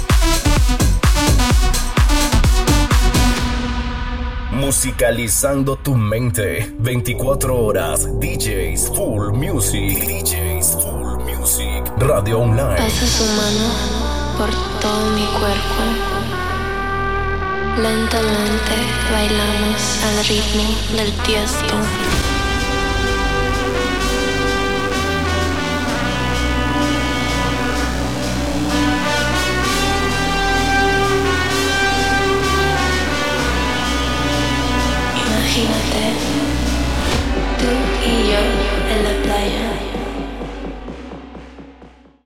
Indicatiu de la ràdio i tema musical